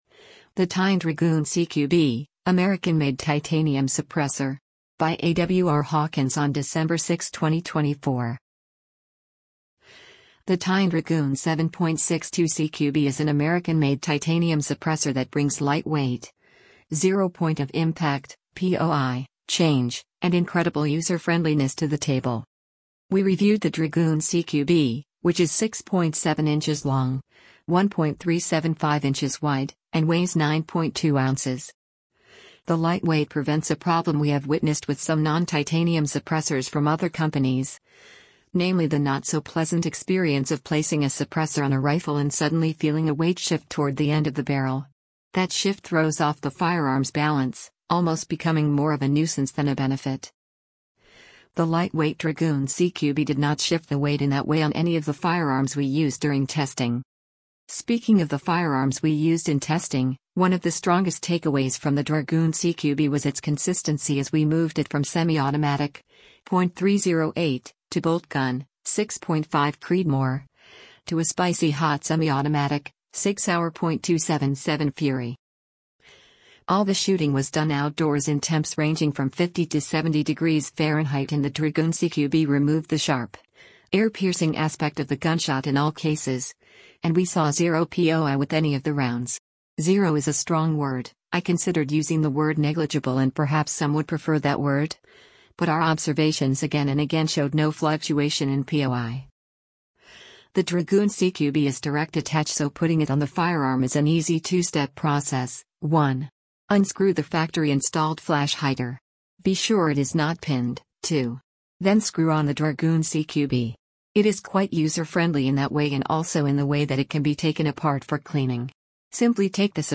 All the shooting was done outdoors in temps ranging from 50 to 70 degrees Fahrenheit and the Dragoon CQB removed the sharp, ear-piercing aspect of the gunshot in all cases, and we saw zero POI with any of the rounds.